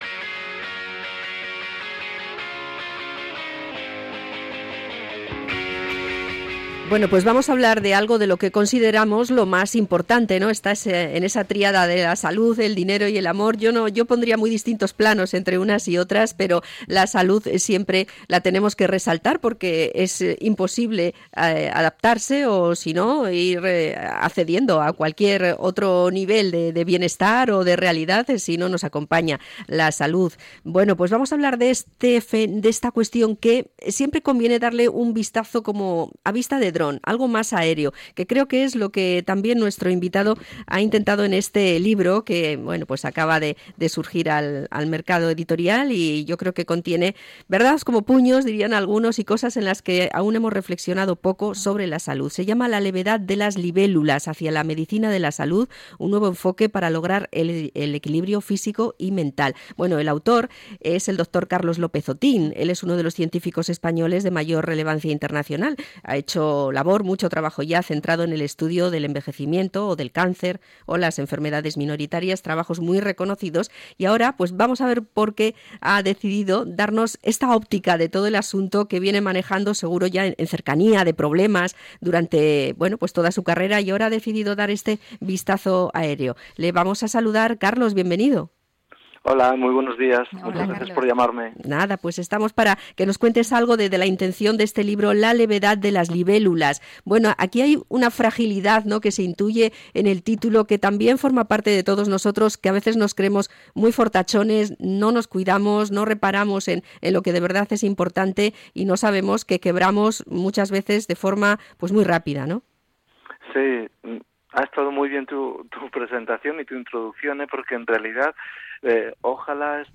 Entrevista al catedrático en Bioquímica y Biología Molecular Carlos López-Otin